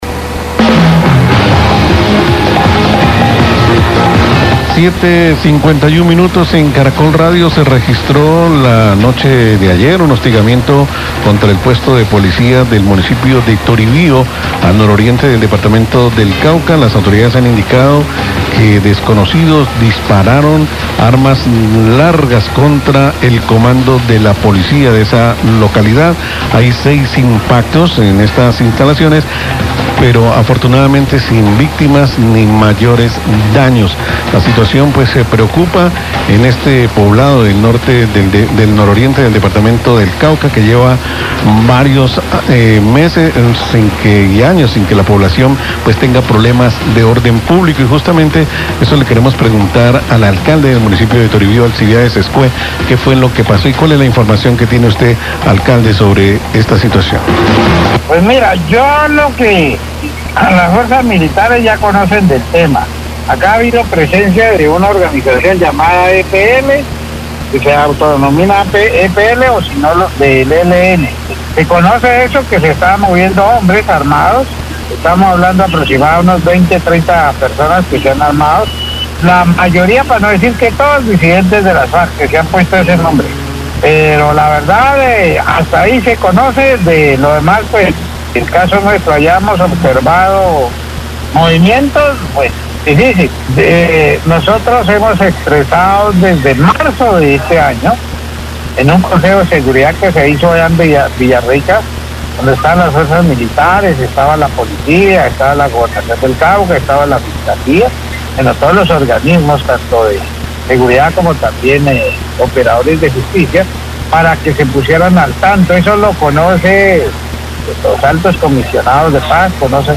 ALCALDE DE TORIBÍO HABLA SOBRE LAS PROTESTAS EN CONTRA DE LA COMPAÑÍA ENERGÉTICA
Radio